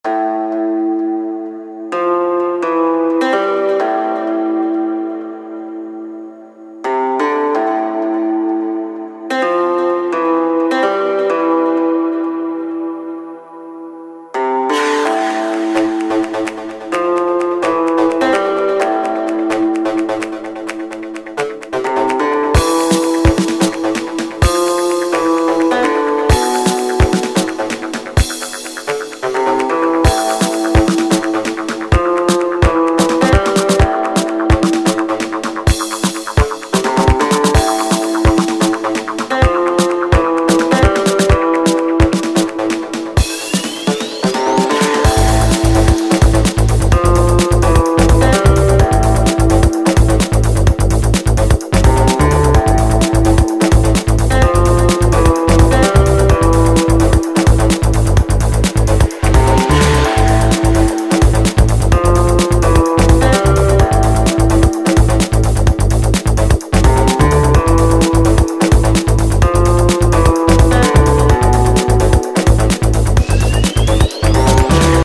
В жизни бы сюда не зашёл, наверное, если бы не старая тема, «гитара» которой писалась из второго Гиперсоника.